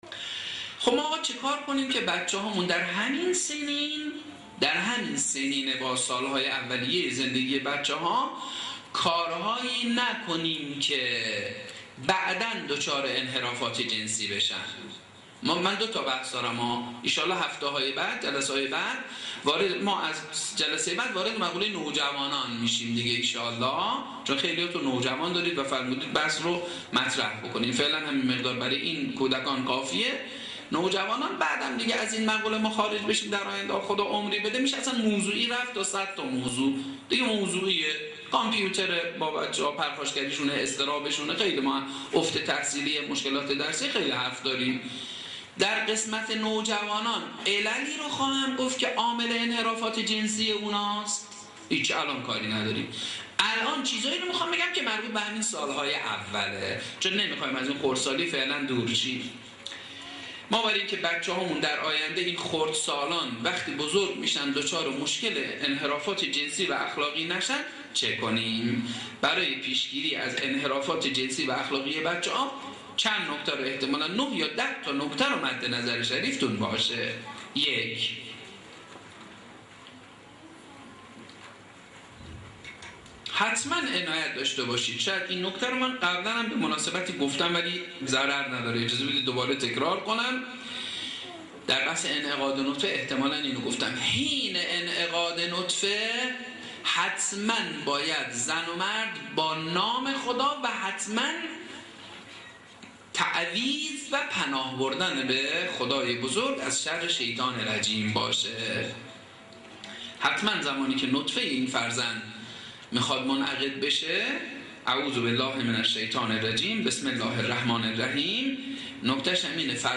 مجموعه سخنرانی